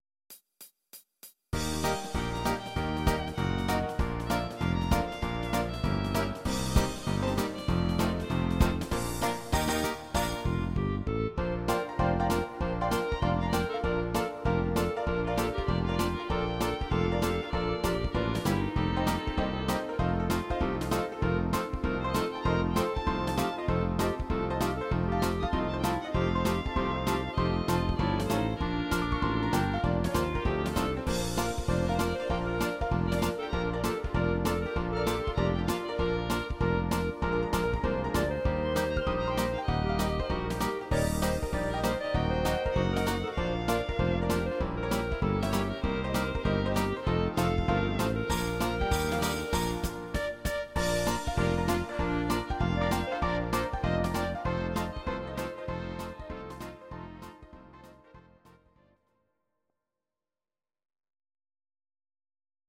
Audio Recordings based on Midi-files
German, 1970s